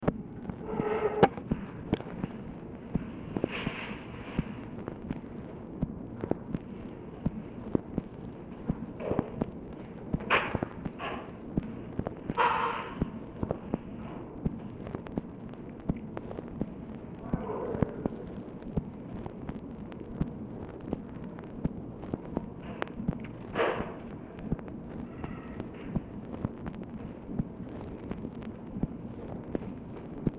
Telephone conversation # 6102, sound recording, OFFICE NOISE, 11/1/1964, time unknown | Discover LBJ
Format Dictation belt